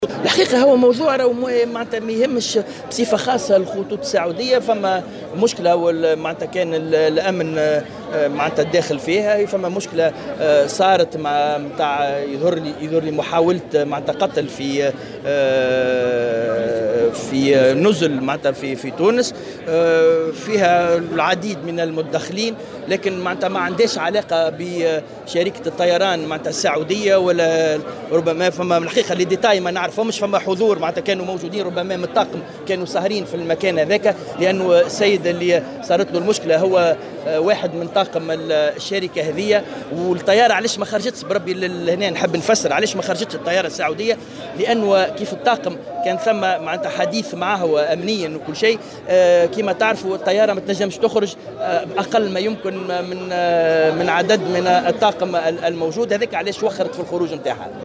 وأوضح بن أحمد في تصريح لمراسلة الجوهرة أف أم، على هامش المؤتمر التأسيسي للاتحاد الدولي لوكلاء كراء السيارات، المنعقد اليوم الأحد في الحمامات، أن عدم مغادرة الطائرة لمطار تونس قرطاج في موعدها كان بسبب النقص الحاصل على مستوى أفراد طاقمها، ما تسبب في تأخير إقلاعها.